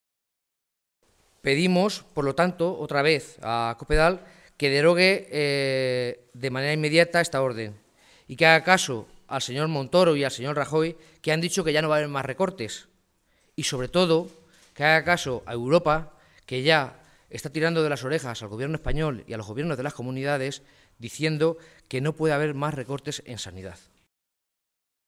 Jesús Fernández Clemente, alcalde de Tembleque
Cortes de audio de la rueda de prensa